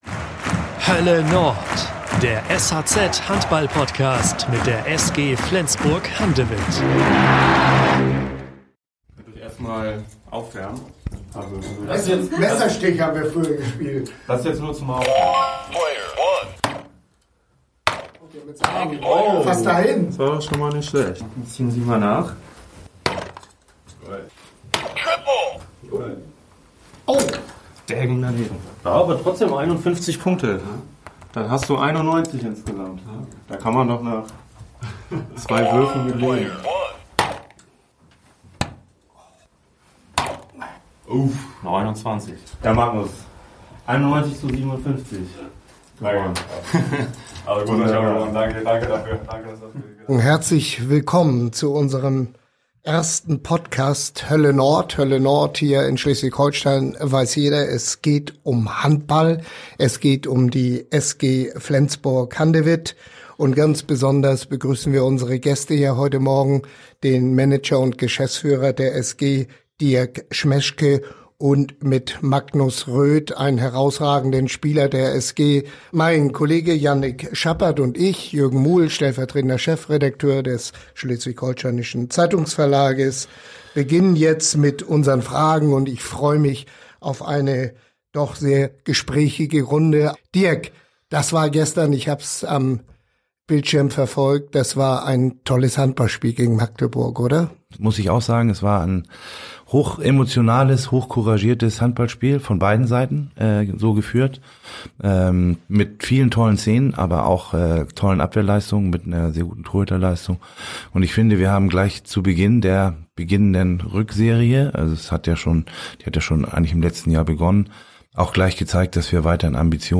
Keine 24 Stunden, nachdem die SG den SC Magdeburg im Bundesliga-Spitzenspiel mit 29:23 besiegt hat, begibt sich das Duo erneut in die „Hölle Nord“ – dieses Mal allerdings nicht in die Flens-Arena, sondern ins Podcast-Studio.